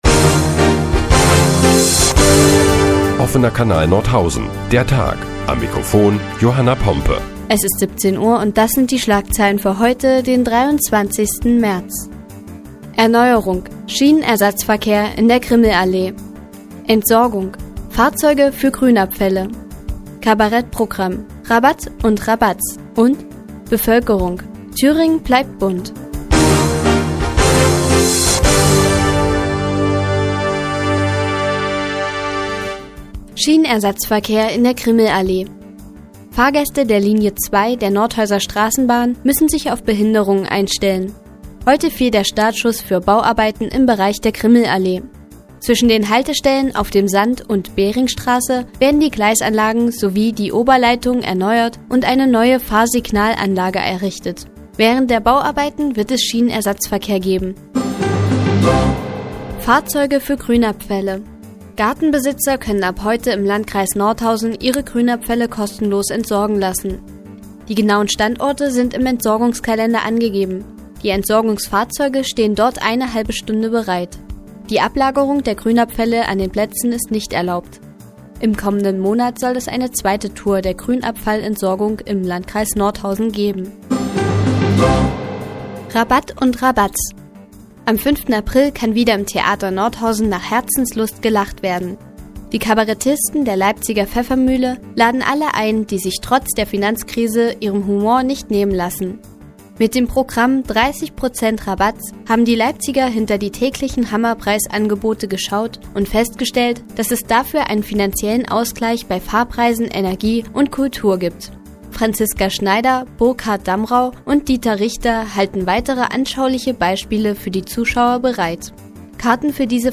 Die tägliche Nachrichtensendung des OKN ist nun auch in der nnz zu hören. Heute geht es unter anderem um Schienenersatzverkehr in der Grimmelallee und Rabatt und Rabatz im Theater.